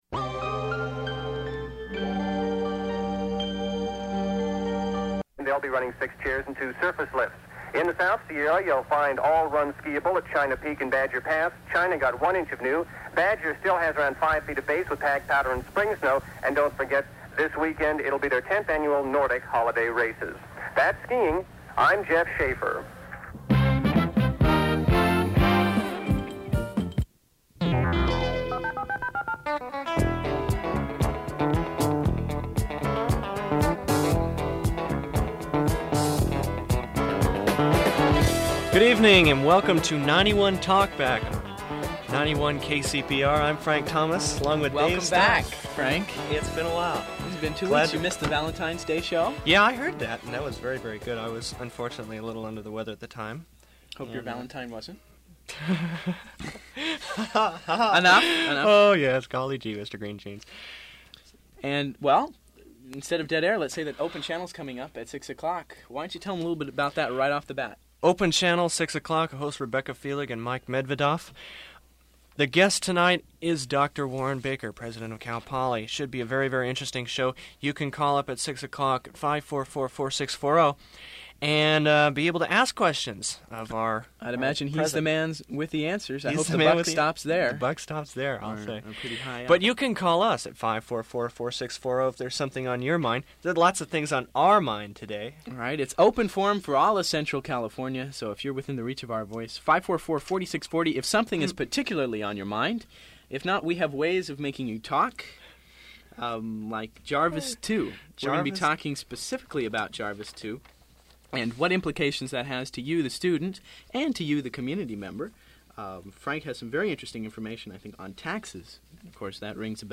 [00:05:40] Caller discusses Jefferson Airplane/Starship
[00:37:42] Dead air ends mid-call with discussion about the Equal Rights Act
[00:53:40] Remote interview (static)